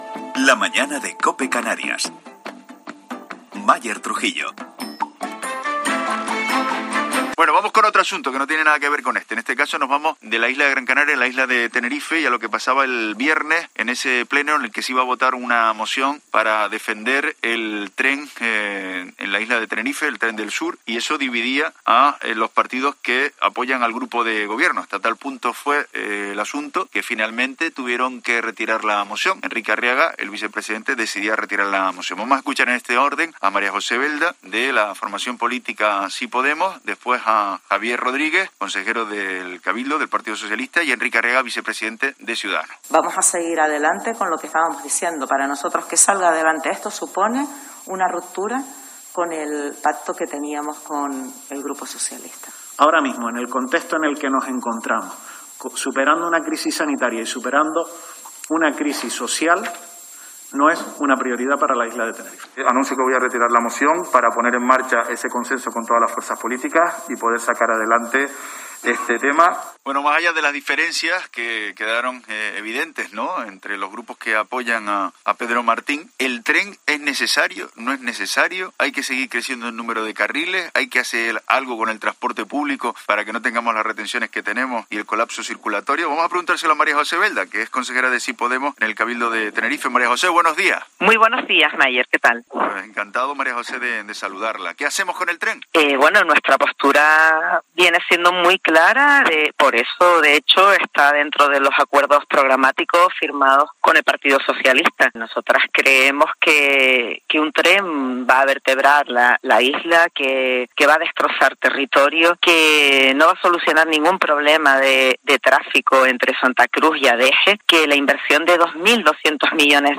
María José Belda, consejera de Sí Podemos, en el Cabildo de Tenerife, se ha mostrado contundente en "La Mañana en Canarias"